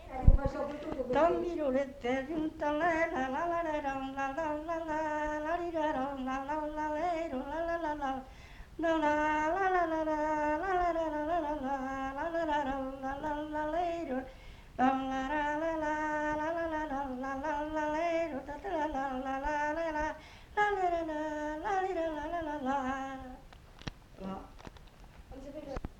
Virolet (fredonné)
Genre : chant
Effectif : 1
Type de voix : voix d'homme
Production du son : fredonné
Danse : virolet